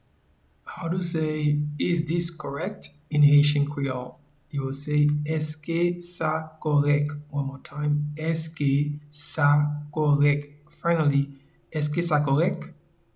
Pronunciation and Transcript:
Is-this-correct-in-Haitian-Creole-Eske-sa-korek.mp3